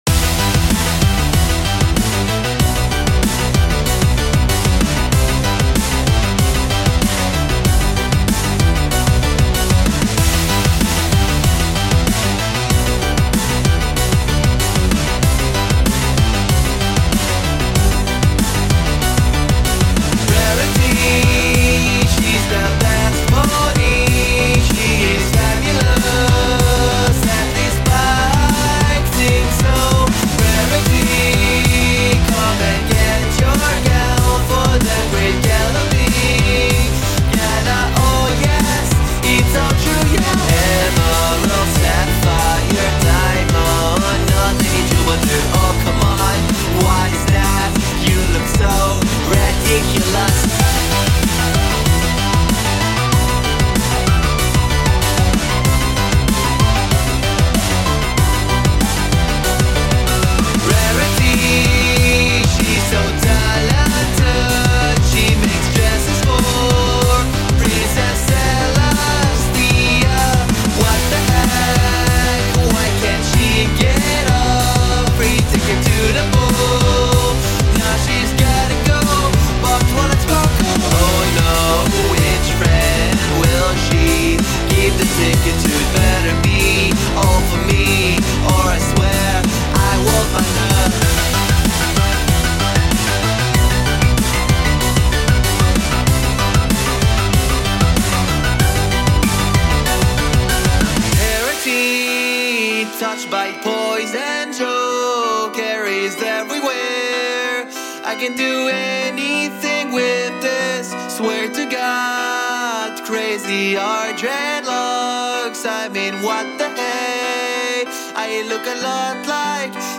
Vocals by Myself